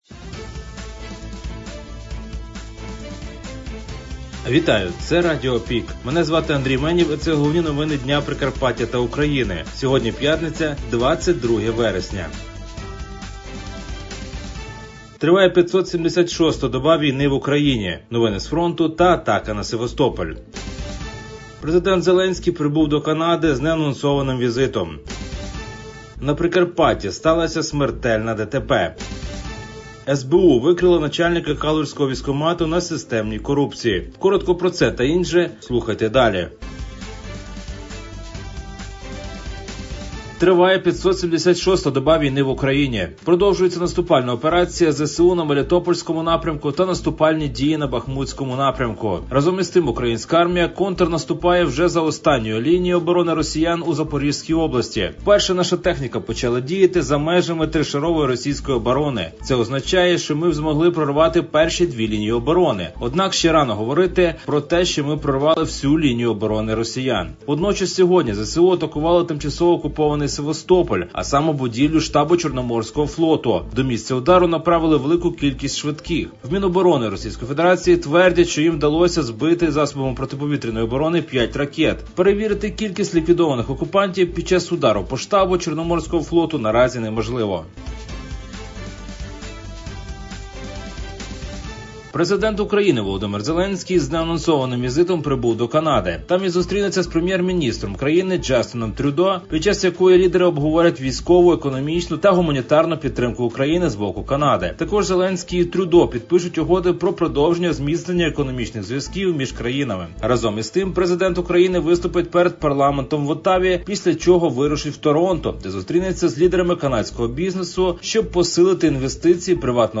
Пропонуємо Вам актуальне за день у радіоформаті.